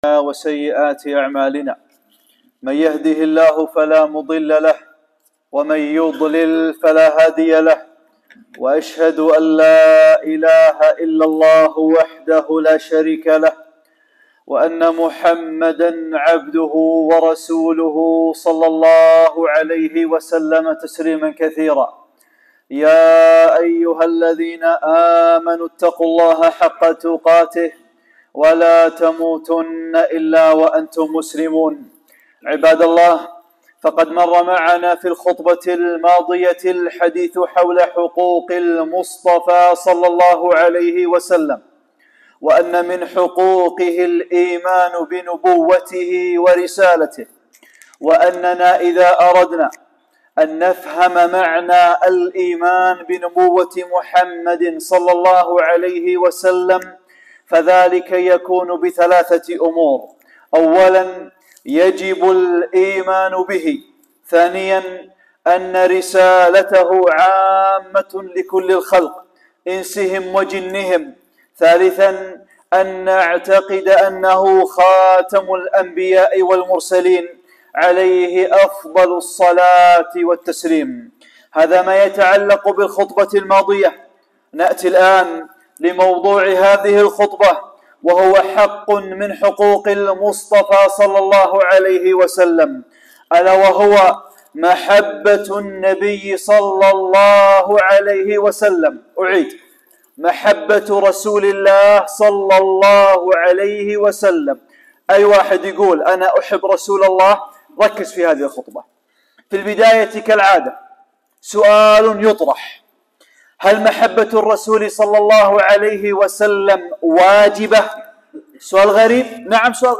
خطبة - (2) محبة النبي ﷺ | حقوق المصطفى ﷺ